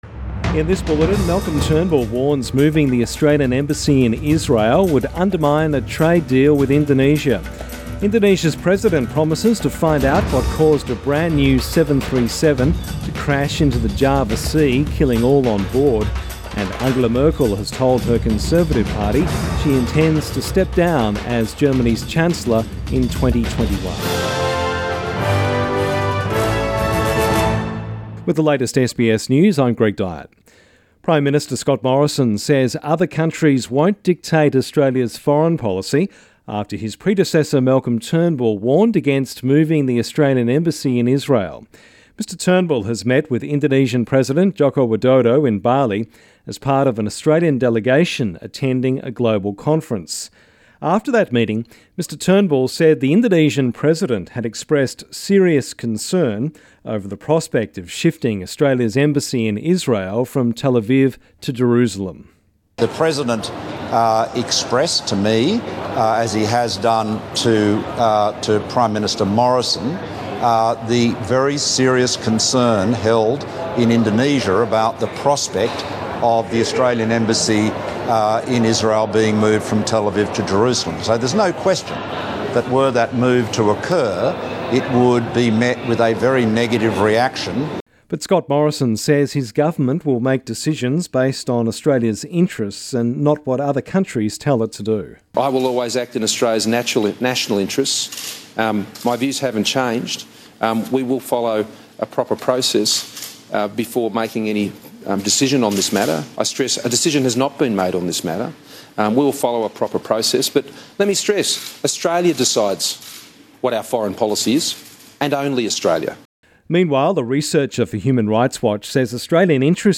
Midday bulletin 30 October